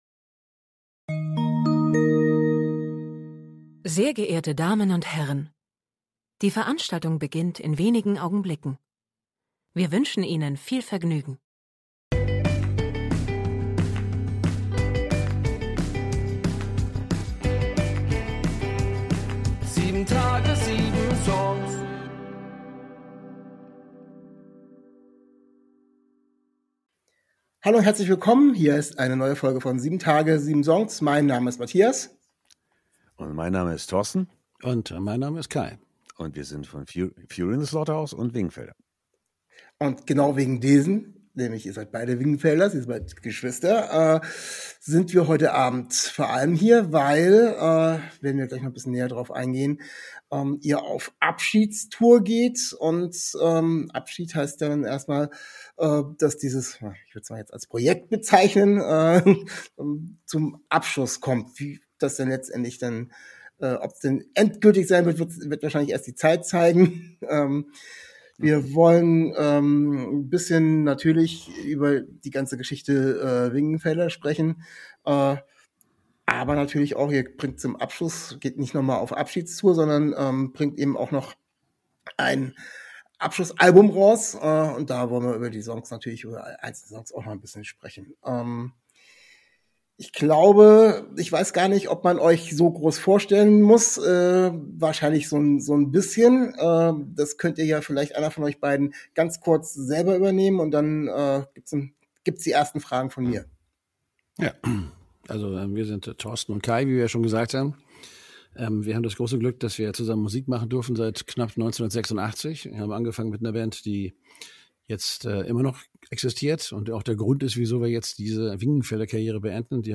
Letzte Episode #4.33 Zu Gast: WINGENFELDER 19. Oktober 2024 Nächste Episode download Beschreibung Kapitel Teilen Abonnieren Zu Gast sind die Brüder Kai und Thorsten von der Band WINGENFELDER. Wir sprechen über ihre Musik, ihr Abschieds- Album un Tour.